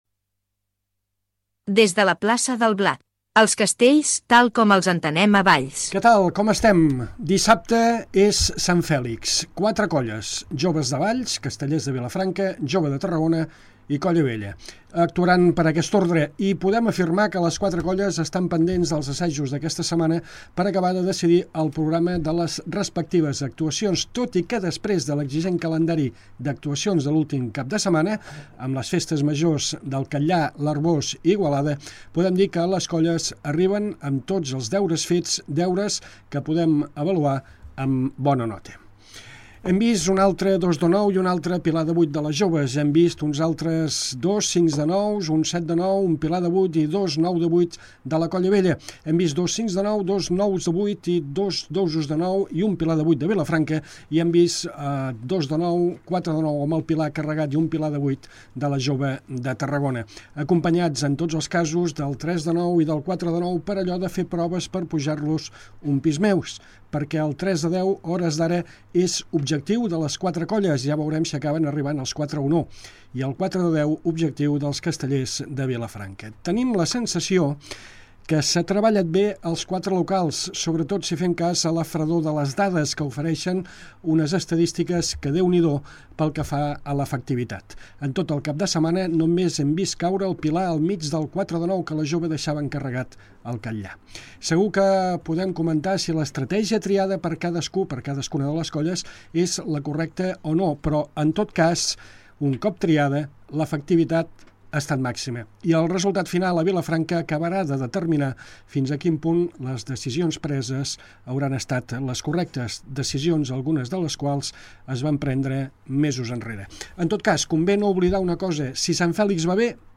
Onzena edició de “Des de La Plaça del Blat” dedicada especialment a una de les cites marcades al calendari casteller: la Diada de Sant Fèlix de Vilafranca del Penedès. Una tertúlia on parlem de les possibles previsions de les 4 colles amb possibilitat de castell de 10 i moltes estructures de gamma extra.